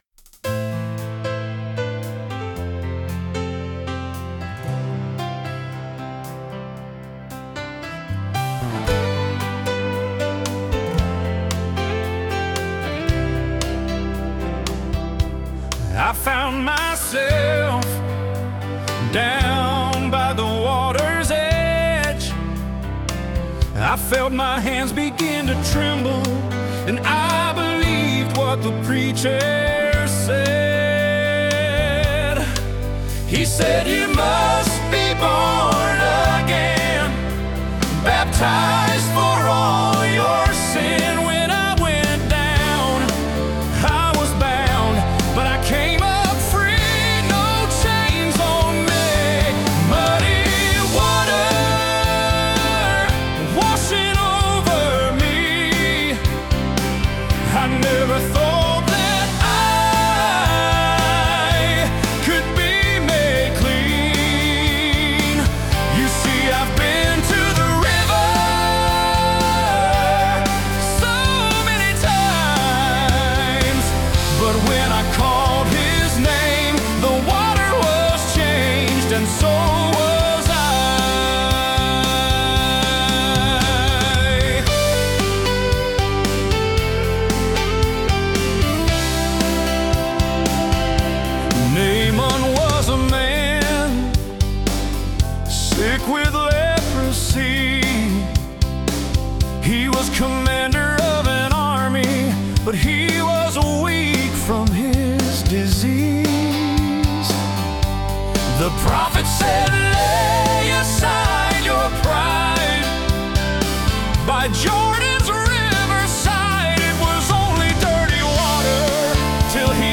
Male Vocalist